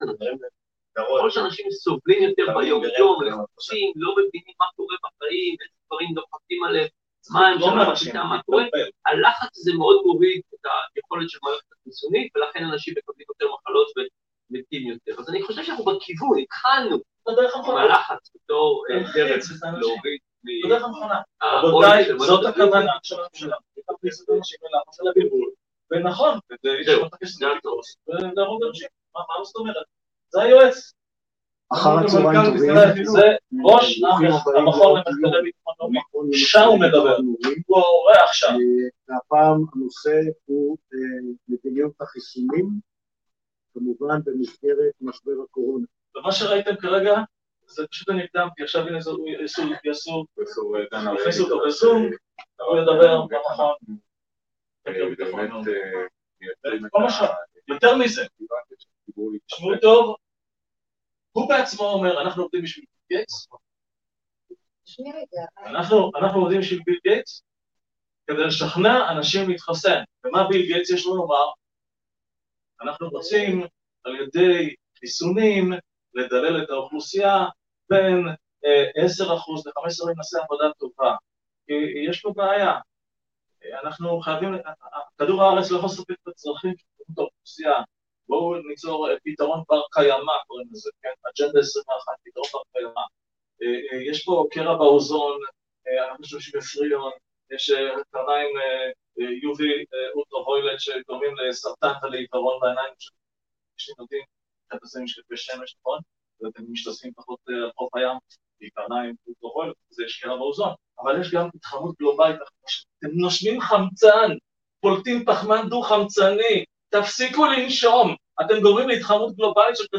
הרצאה בהרצליה עם מצגת והוכחות